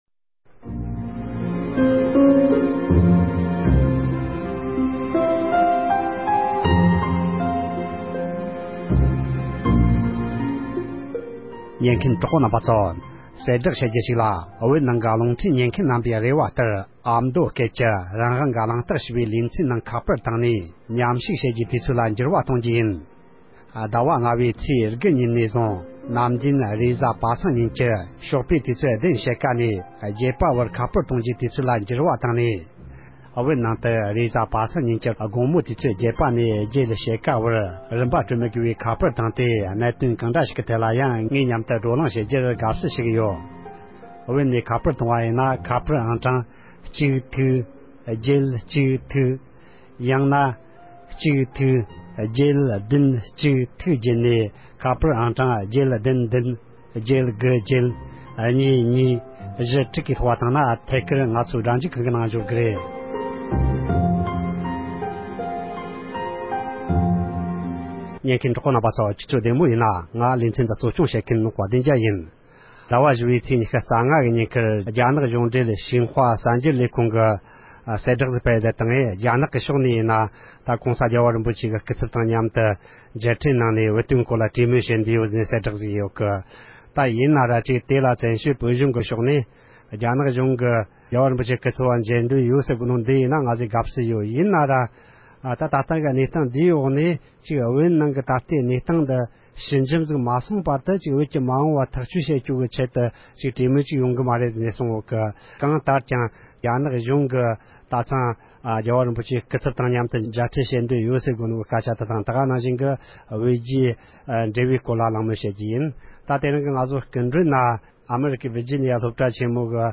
བོད་རྒྱའི་འབྲེལ་མོལ་སྐོར་བགྲོ་གླེང་བྱས་པའི་ལེ་ཚན།